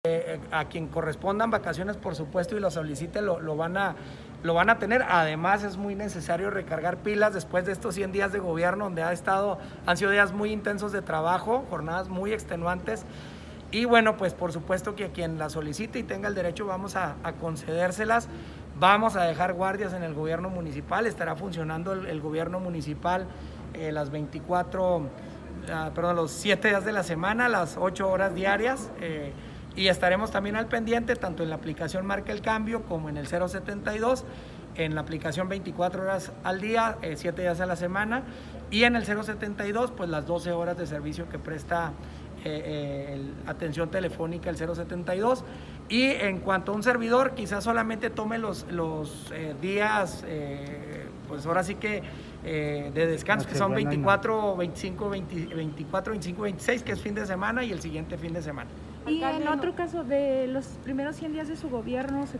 Chihuahua.- Los funcionarios del municipio que soliciten y les correspondan vacaciones podrán obtenerlas, según declaró el alcalde de Chihuahua, Marco Bonilla